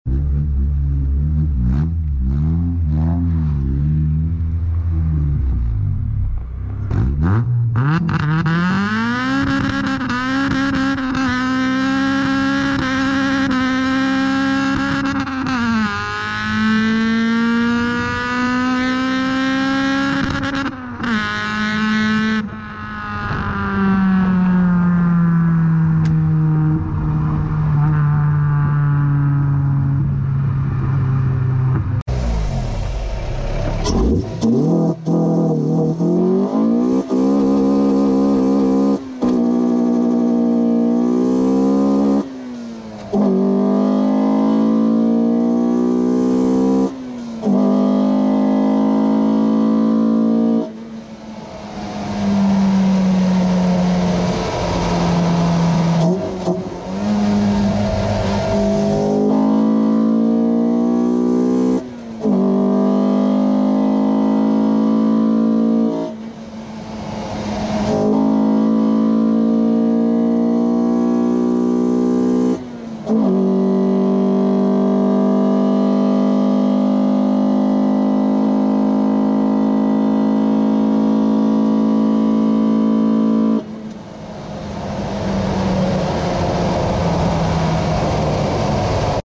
🔥🔥PURE NA CAMMED B18 FPV sound effects free download
🔥🔥PURE NA CAMMED B18 FPV EXHAUST AND INDUCTION NOISE!!!